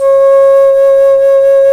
Index of /90_sSampleCDs/Roland LCDP04 Orchestral Winds/FLT_Alto Flute/FLT_A.Flt vib 1